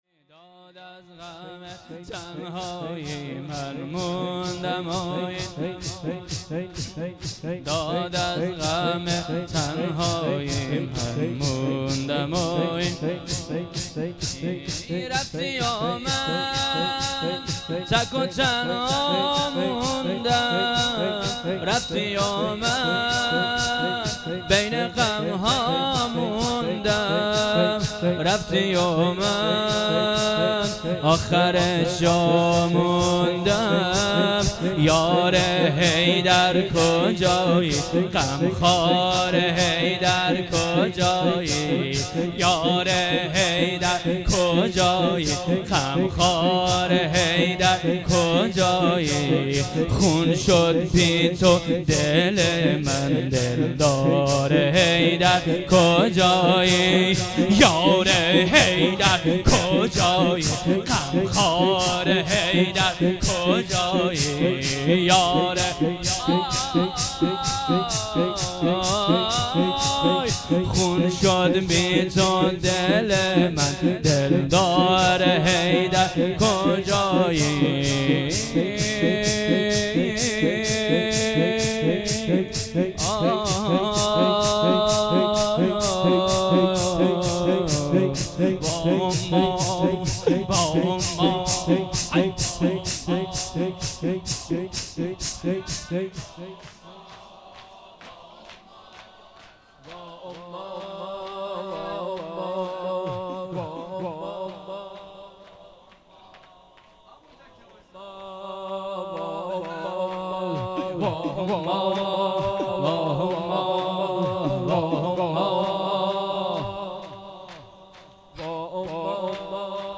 شور
شب سوم دهه‌ی دوم فاطمیه ۹۸